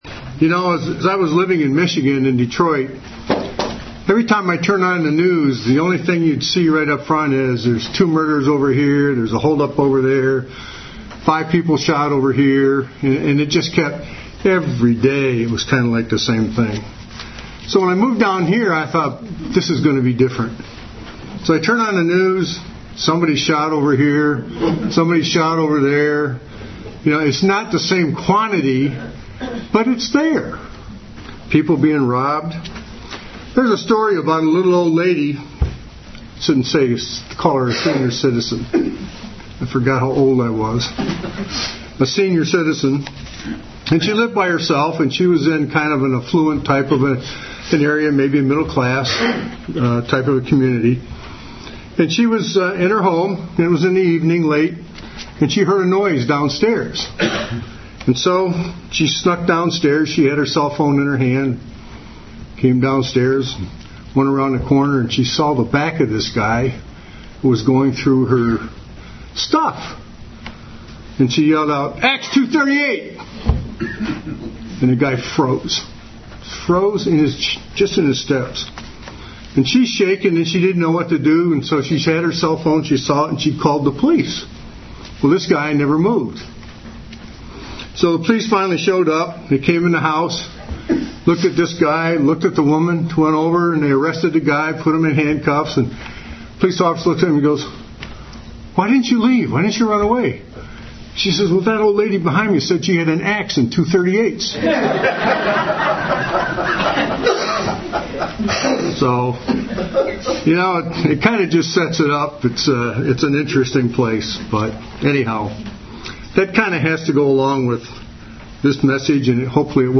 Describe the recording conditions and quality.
Given in Cincinnati North, OH